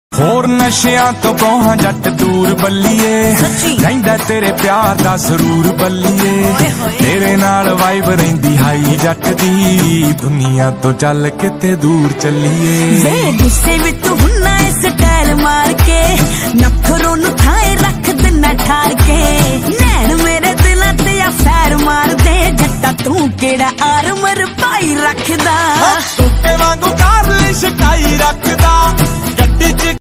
punjabi ringtone mp3